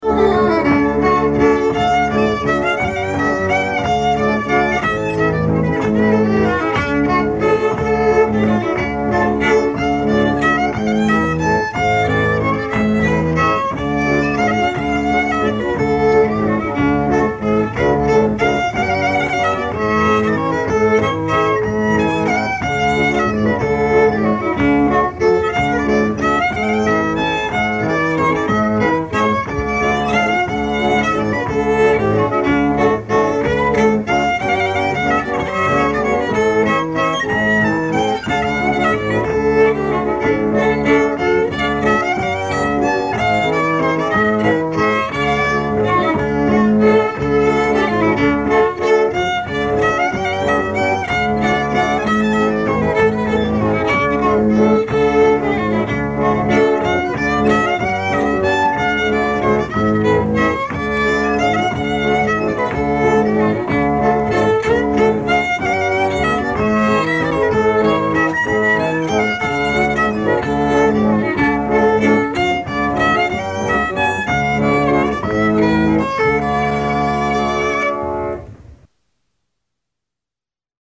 pump organ